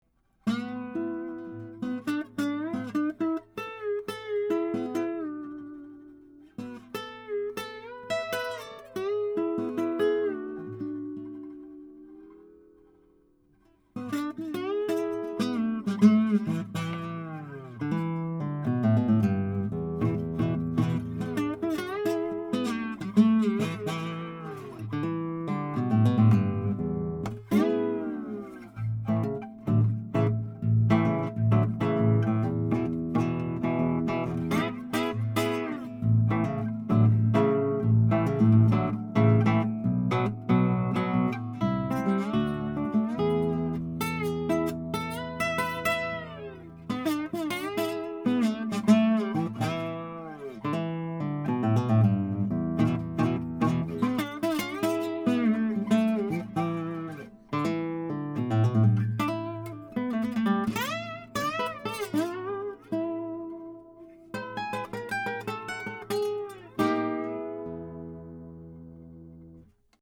Flickingers's have a great vintage character and tone that imparts a creaminess and heft to your tracks.
Tracked using a SAMAR Audio modded AKG C1000S (left channel) and a vintage AKG C451/CK1 (right channel) through a Metric Halo ULN-8 interface:
NYLON STRING HARP GUITAR